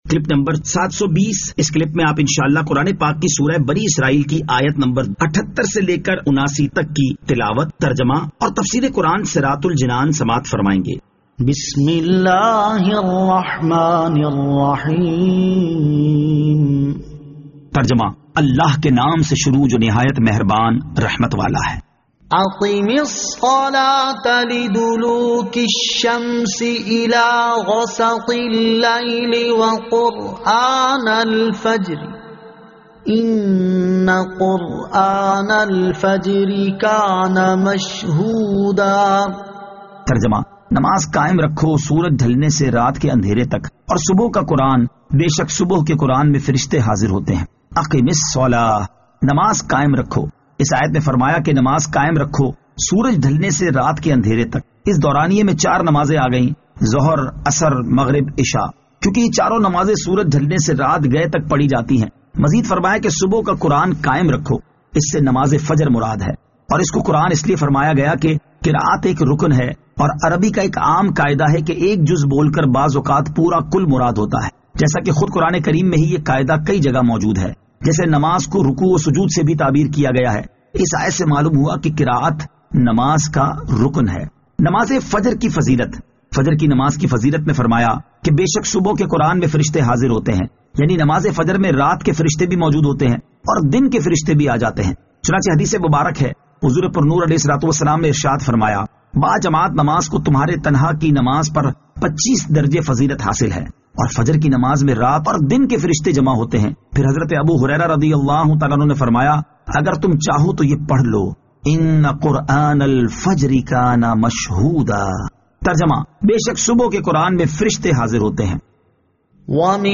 Surah Al-Isra Ayat 78 To 79 Tilawat , Tarjama , Tafseer